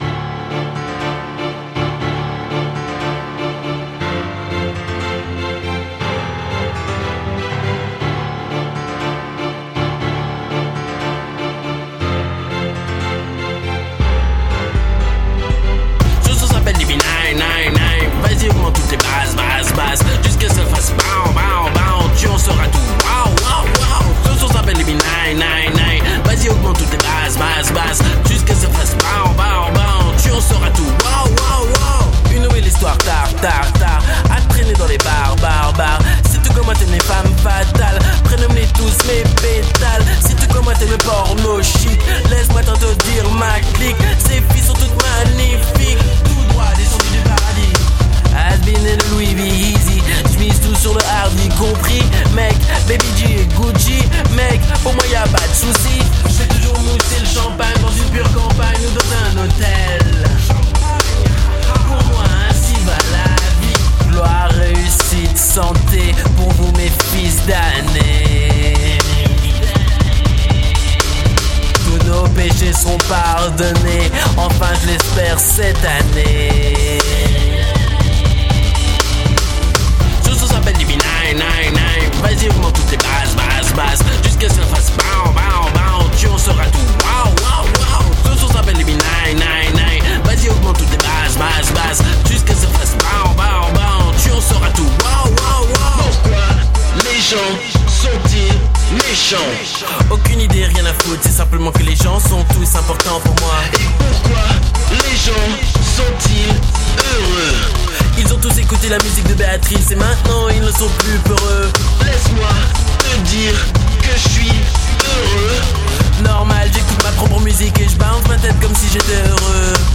rappeur